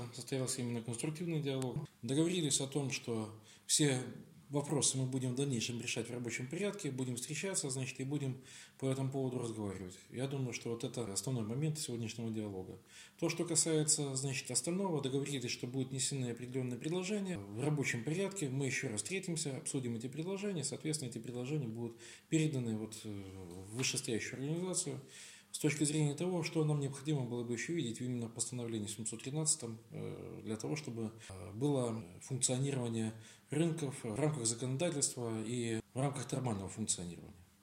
baczenko-ip-sinhron-vypuska.wav